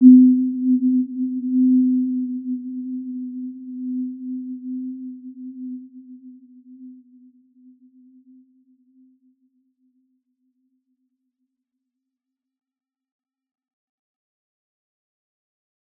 Warm-Bounce-C4-p.wav